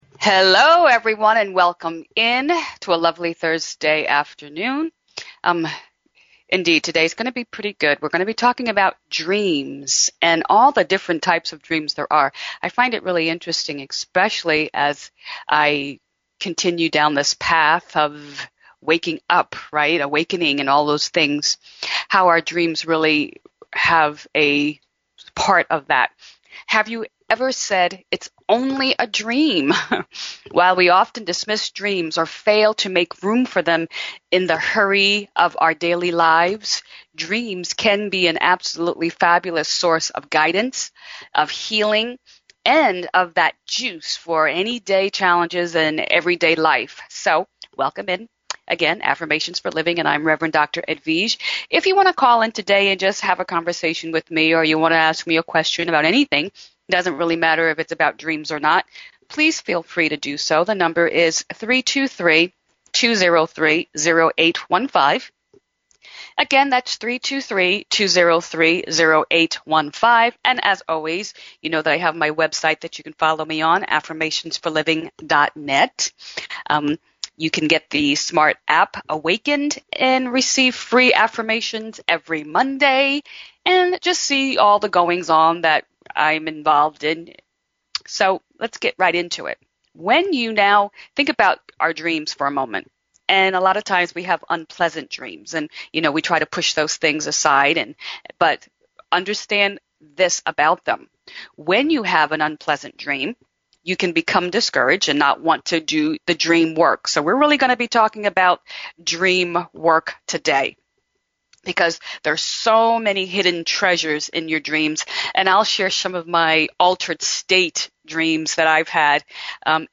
Talk Show Episode, Audio Podcast, DREAM TIME and Answers and Perspectives from your Dreams on , show guests , about Affirmations,Dreaming,Dreams,Guidance,Healing, categorized as Psychology,Spiritual,Divination